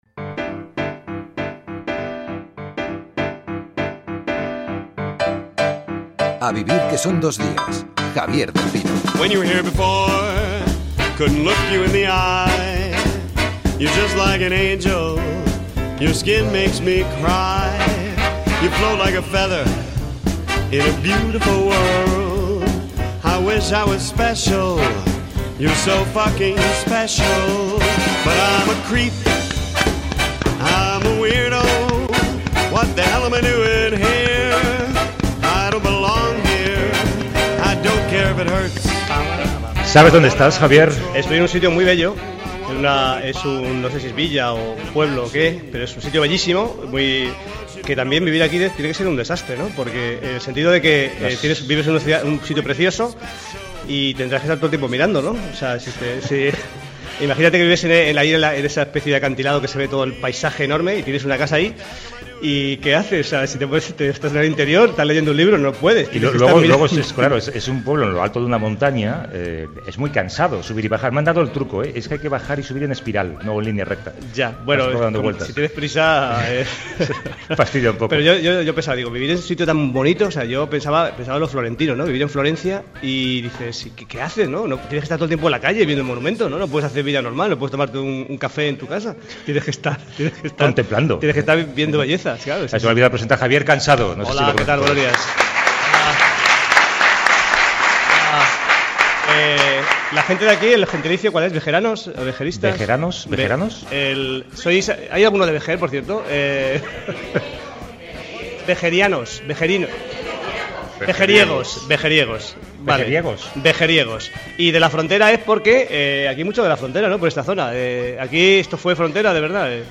29f80f8b2ddee6e15e034f41ff3e66df34010b8f.mp3 Títol Cadena SER Emissora Ràdio Barcelona Cadena SER Titularitat Privada estatal Nom programa A vivir que son dos días Descripció Espai fet al I Congreso Mundial del Bienestar celebrat a Vejer de la Frontera (Cadis).
Entrevista al filòsof Patrick Viveret.